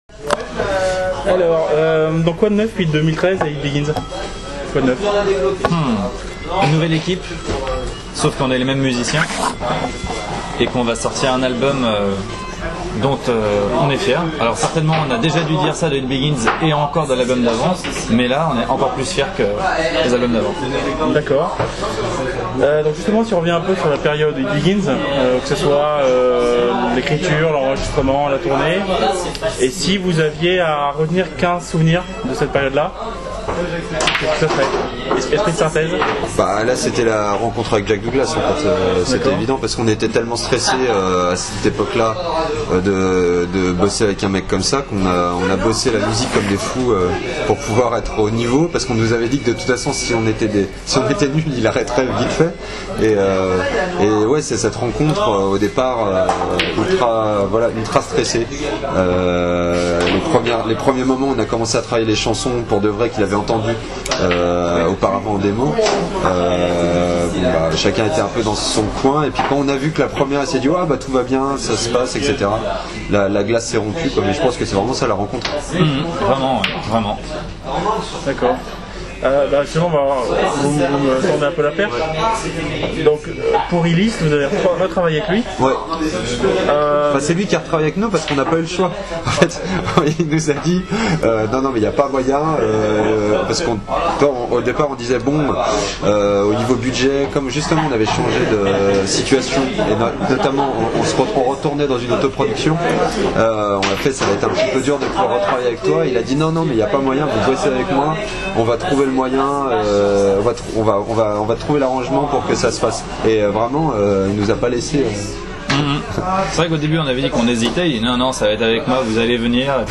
BLACKRAIN (Interview